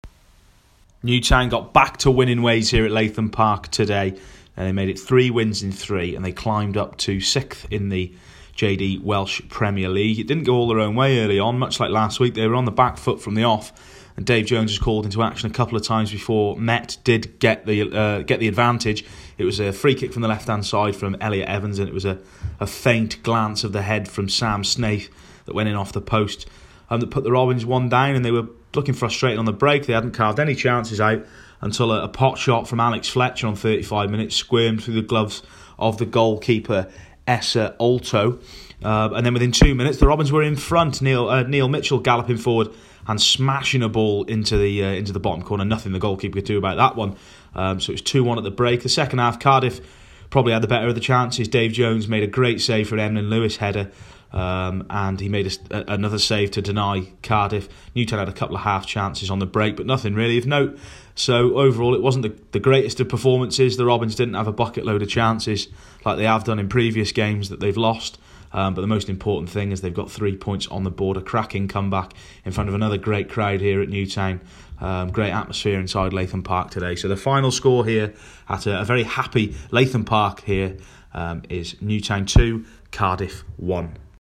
AUDIO REPORT - Robins 2-1 Cardiff Met